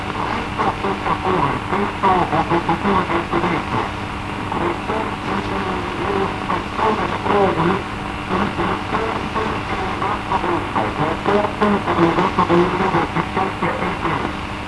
Psicofonías